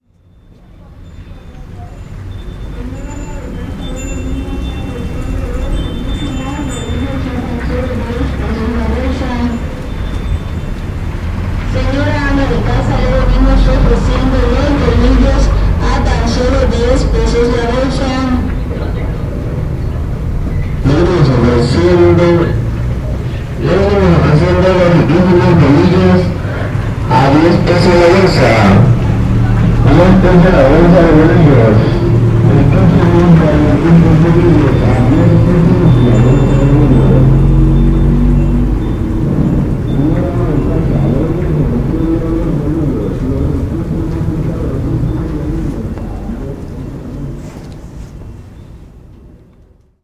En esta ocasión queremos compartirles el anuncio de un artículo que usualmente encontramos en las panaderías o supermercados, los bolillos, este anuncio nos muestra además cómo los niños se involucran en la actividad de sus padres los fines de semana, en que no van a la escuela.